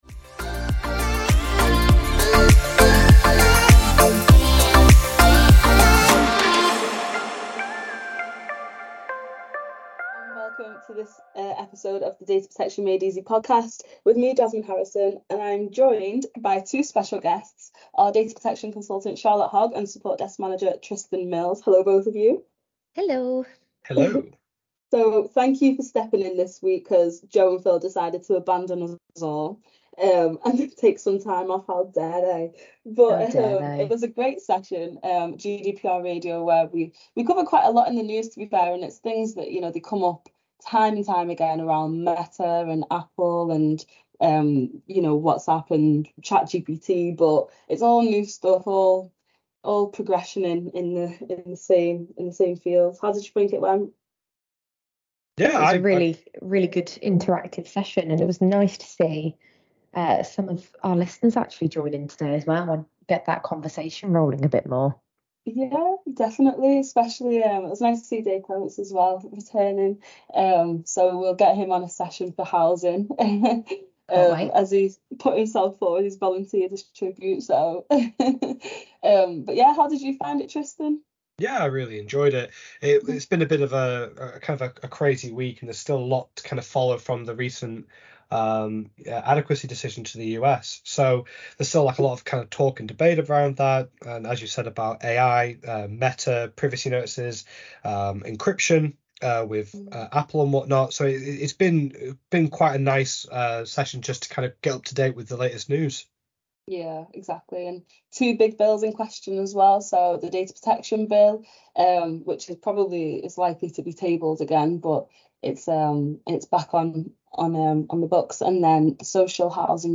If you enjoyed this episode of the Data Protection Made Easy podcast why not register for one of our upcoming episodes and join our live audience.